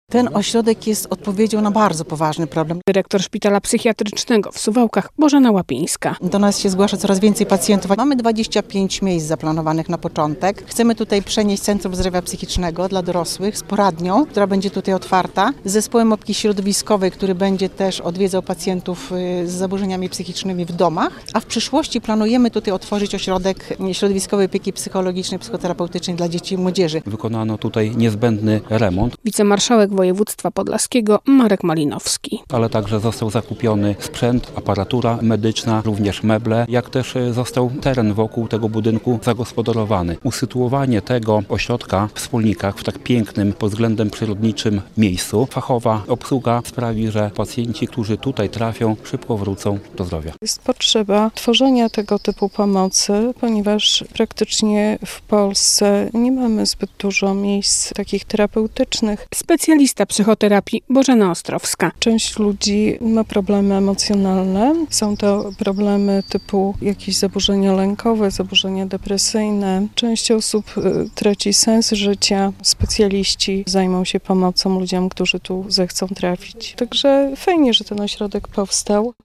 Ośrodek terapeutyczny w Smolnikach - relacja